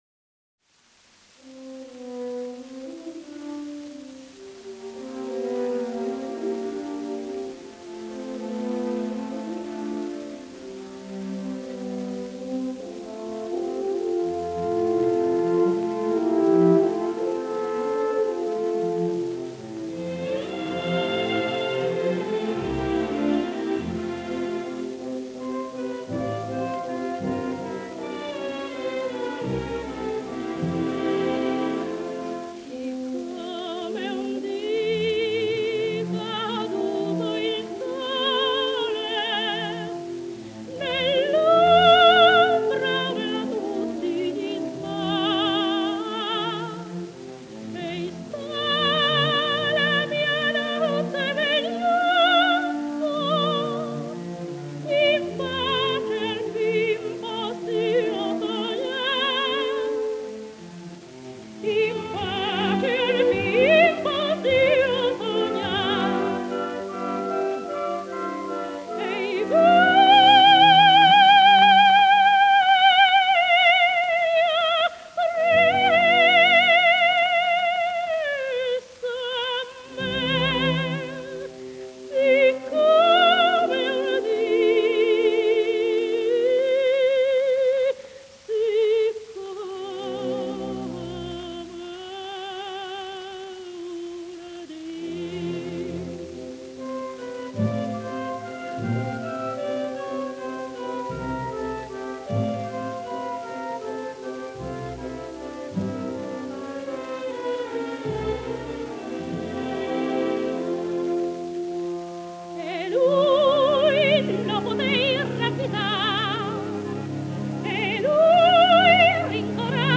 ДАЛЬ MОHTE (Dal Monte) Тоти (наст. имя и фамилия - Антониетта Mенегелли, Meneghelli) (27 VI 1893, Мольяно-Венето - 26 I 1975) - итал. певица (колоратурное сопрано).
Редкий по красоте тембра, гибкости и яркости звучания голос, виртуозное вок. мастерство, музыкальность и артистич. дарование поставили Д. М. в ряд выдающихся оперных певиц мира.